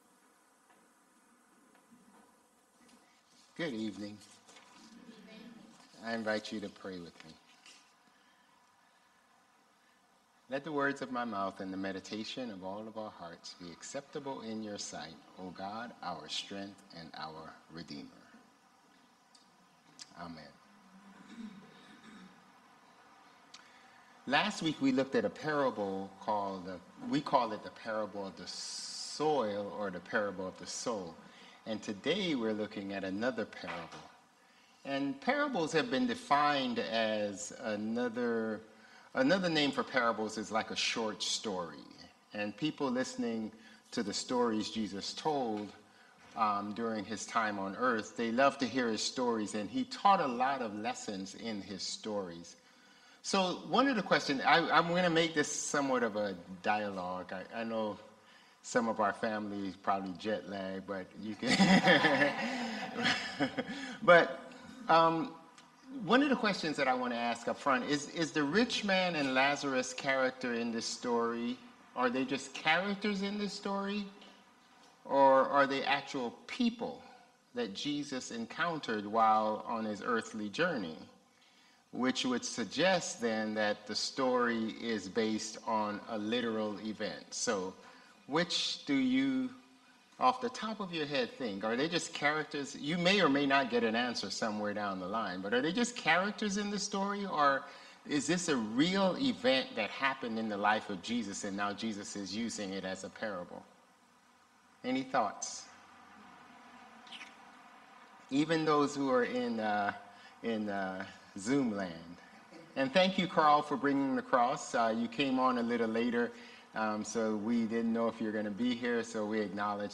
Sermons | Bethel Lutheran Church
November 3 Worship